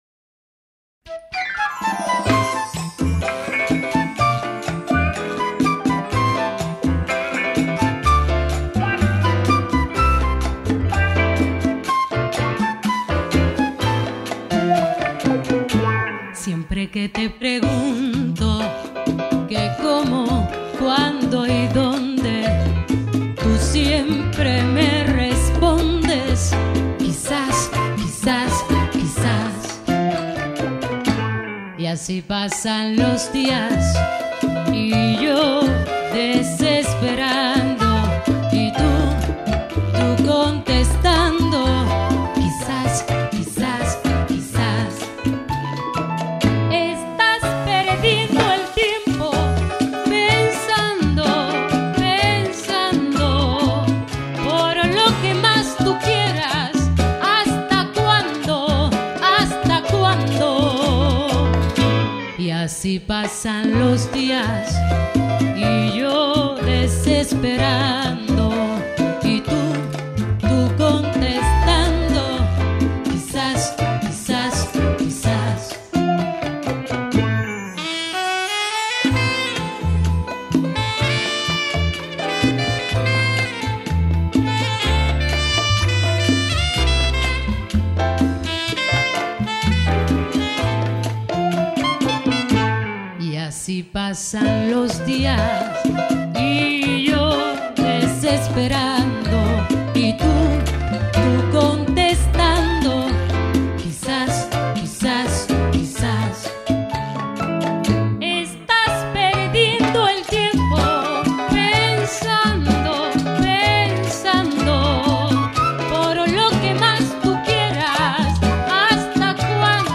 Ici je ne connais pas l'interprète féminine ...